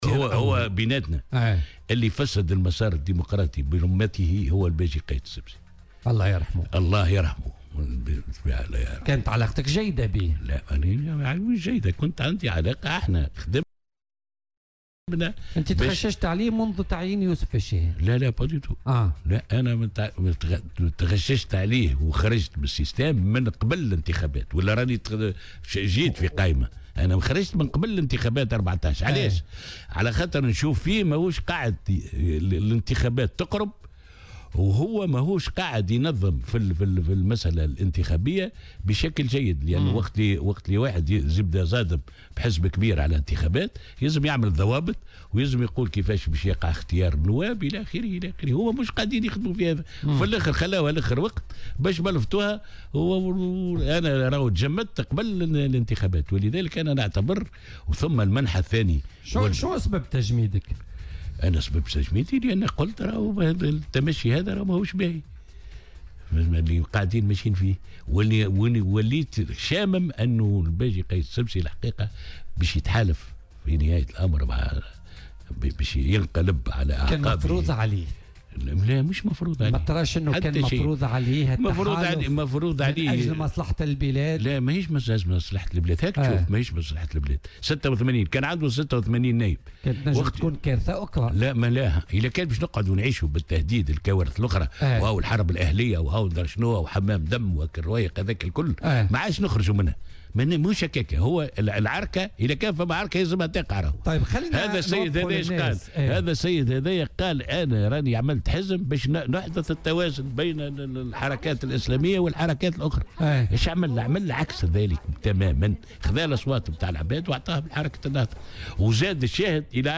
وأضاف في مداخلة له اليوم في برنامج "بوليتيكا" أنه قد وقع تجميد عضويته في الحزب قبل انتخابات 2014 لأنه انتقد التمشي الذي توخاه الرئيس الراحل في تلك المرحلة وكذلك تحالفه فيما بعد مع حركة النهضة.